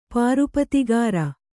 ♪ pārupatigāra